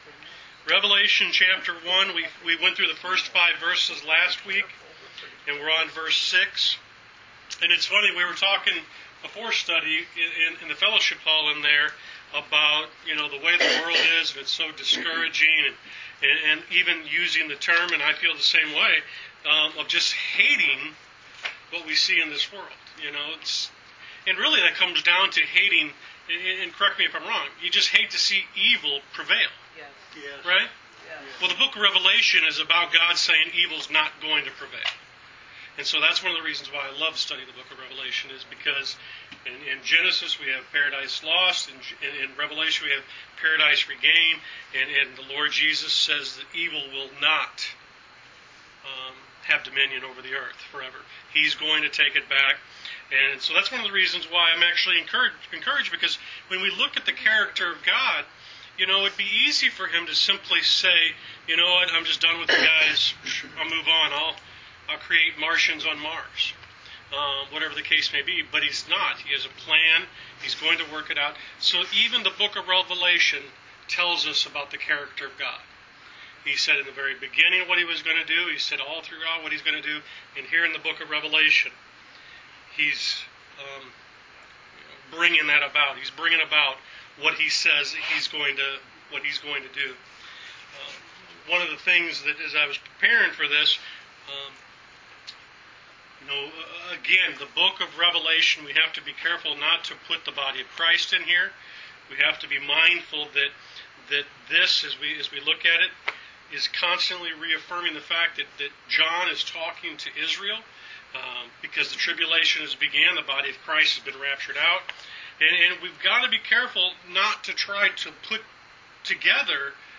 Wednesday Bible Study: Rev Ch 1 Pt 2